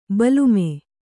♪ balume